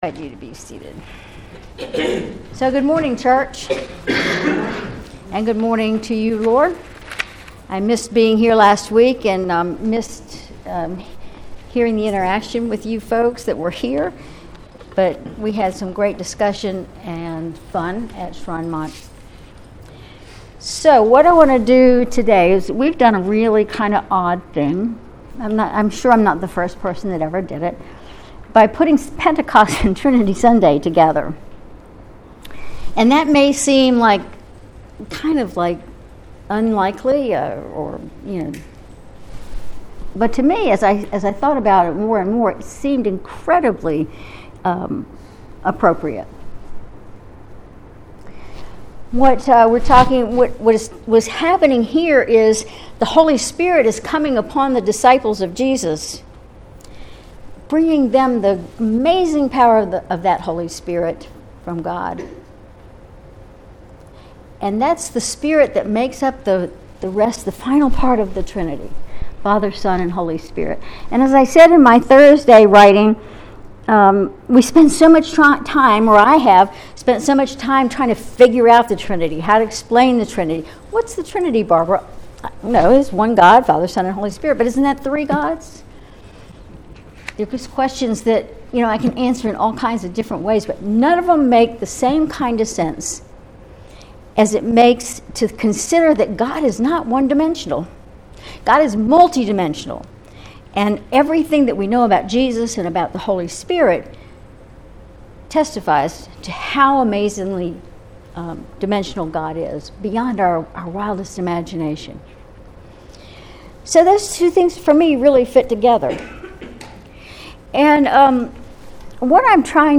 Sermon May 26, 2024